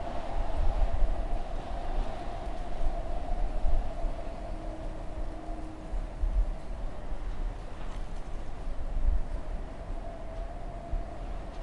风的声音
描述：一个刮风的日子，风的声音在门框周围吹来
标签： 哀嚎 大风 幽灵 可怕 怪异 嚎叫 闹鬼 阵风 背景声 环境音 风暴 悬念
声道立体声